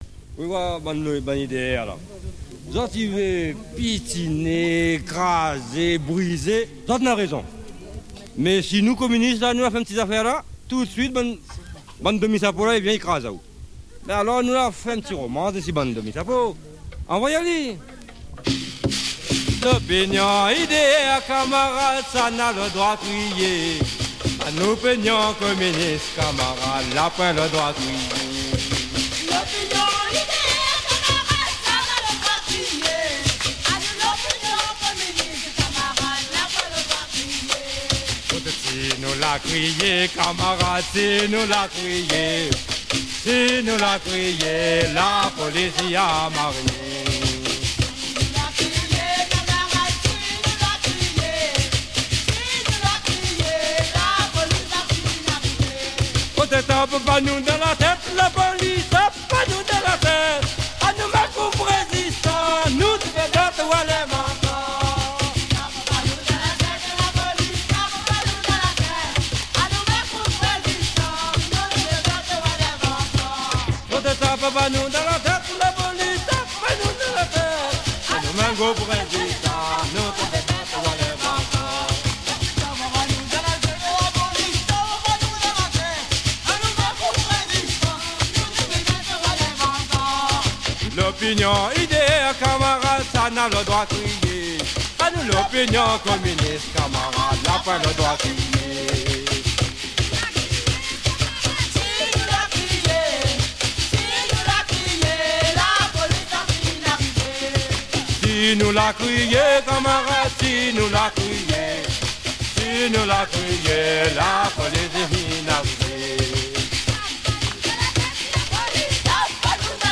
PEUPLE DU MALOYA : Peuple de la Réunion - Patrimoine musical de l'Océan Indien